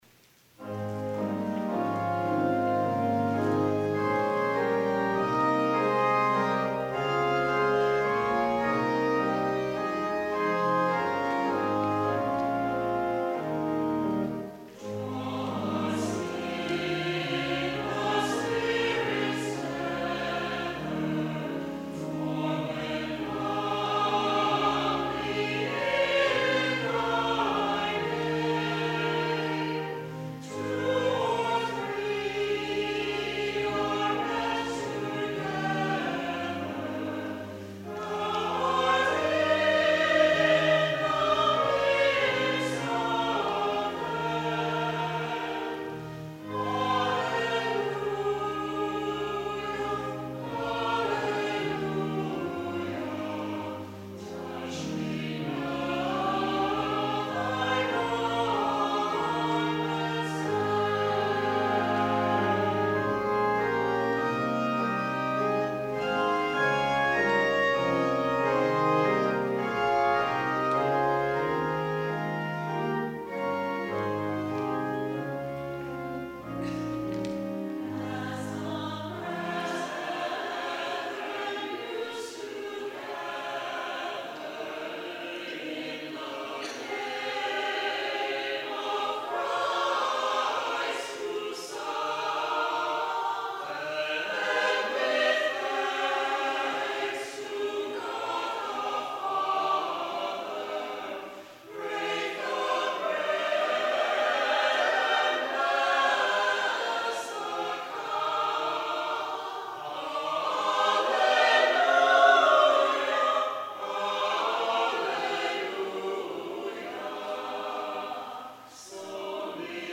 ANTHEM  Draw Us in the Spirit’s Tether Harold Friedell, 1905-1958
organ